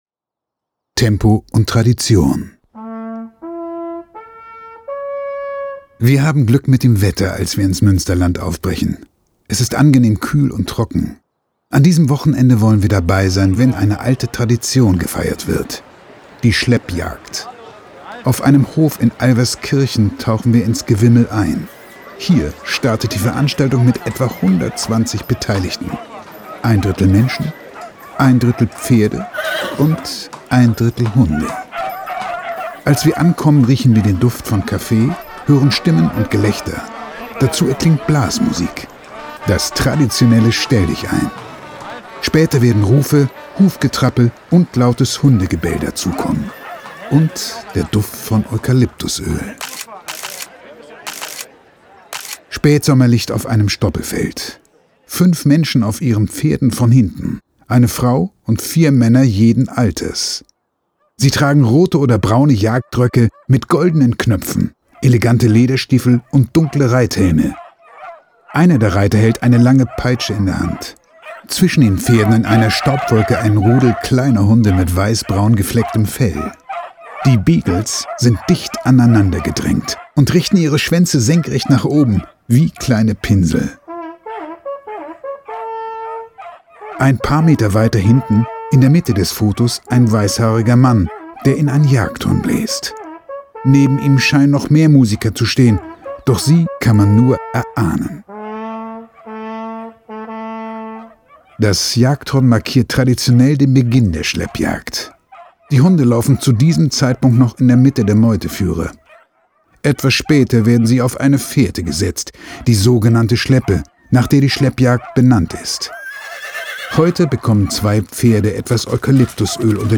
Unser Hörbuch genießen Sie am besten mit Kopfhörern .
pferde2020_hoerbuch_taste_7__tempoundtradition_outroanmutundatem__master.mp3